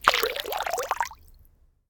water-pour-04
bath bathroom bubble burp click drain dribble dripping sound effect free sound royalty free Nature